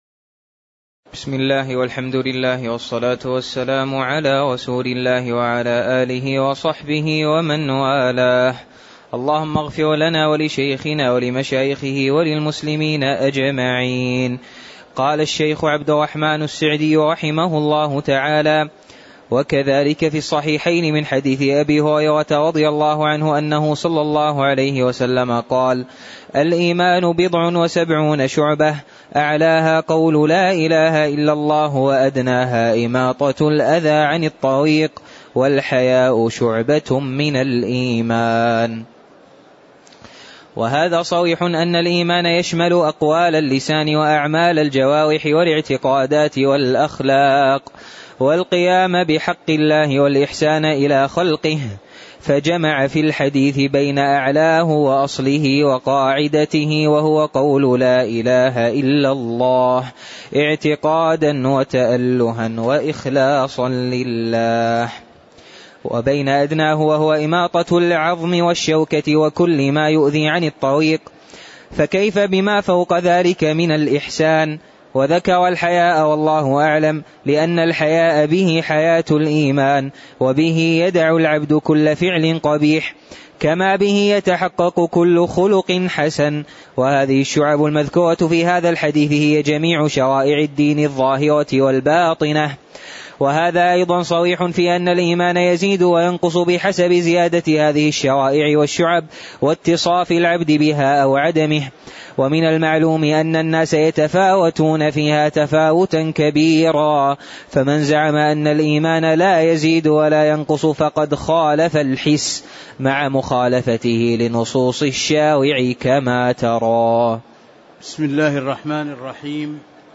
تاريخ النشر ١٥ ربيع الثاني ١٤٤٥ هـ المكان: المسجد النبوي الشيخ